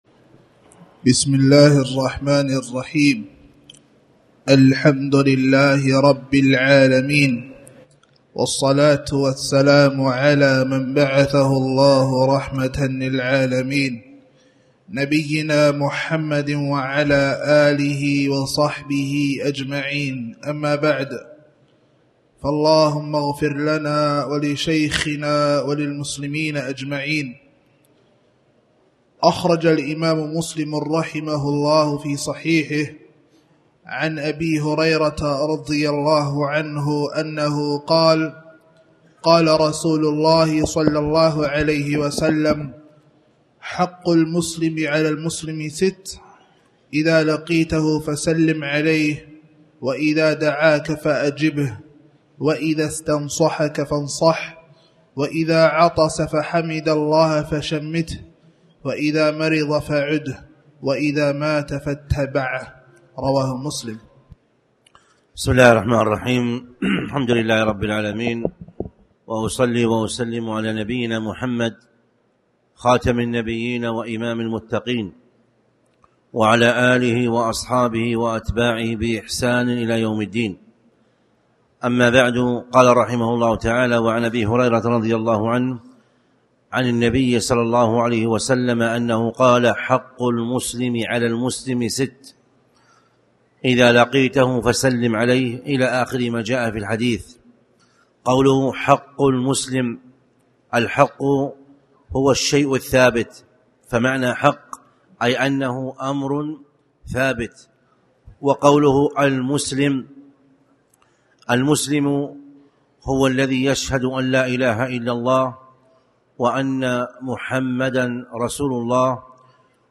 كتاب الصلاة - نهاية الدرس مقطوع
تاريخ النشر ٢٦ ربيع الأول ١٤٣٩ هـ المكان: المسجد الحرام الشيخ